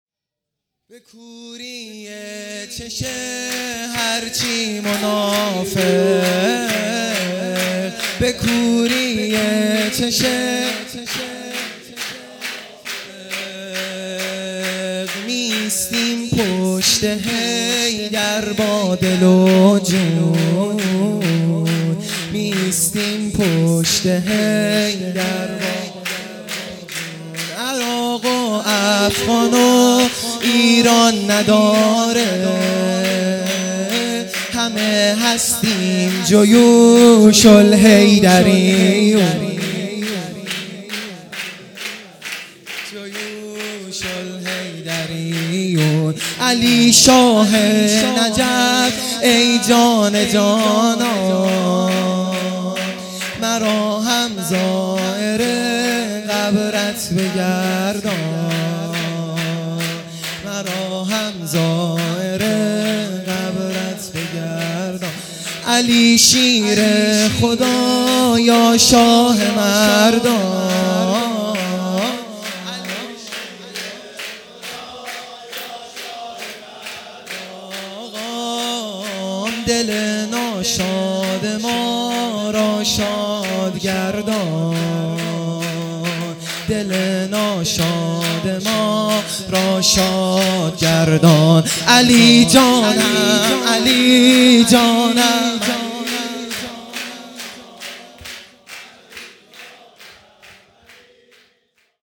سرود
عید غدیر خم